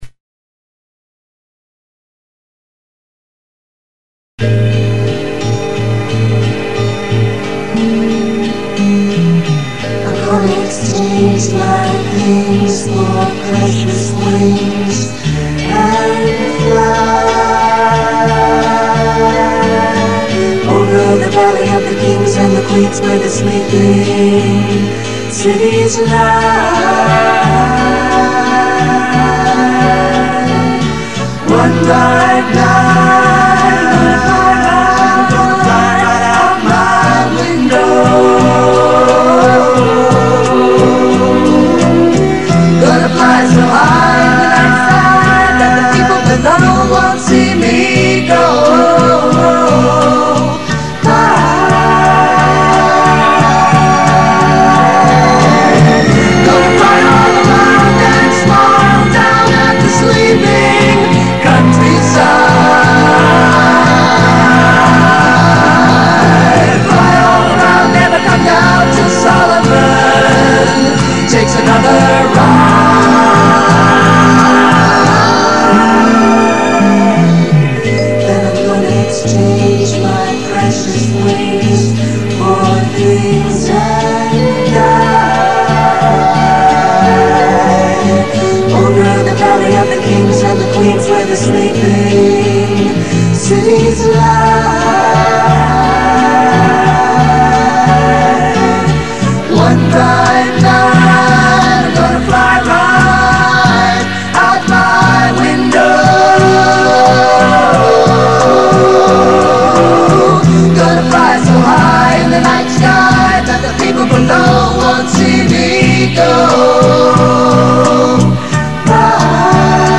And i'm convinced that it's a recording that synanon members did in the 60's or 70's. It has that sound about it.